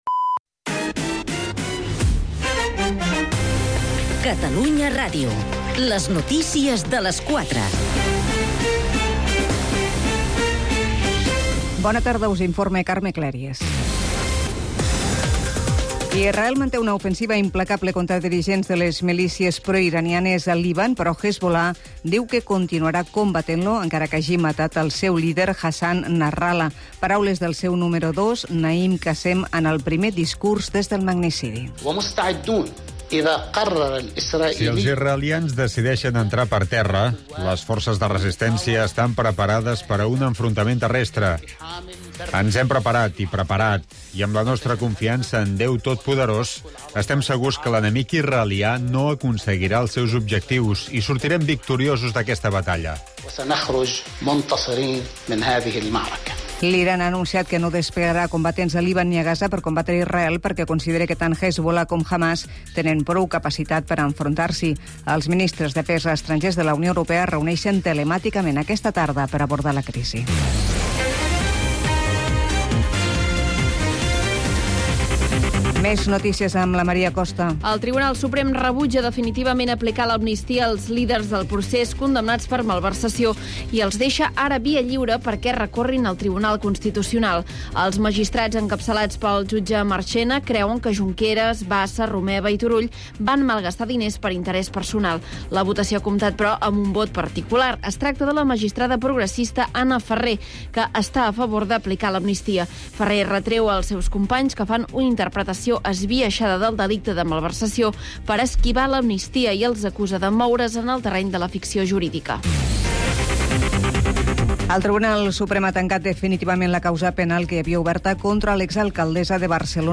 Un programa que, amb un to proper i dists, repassa els temes que interessen, sobretot, al carrer. Una combinaci desacomplexada de temes molt diferents.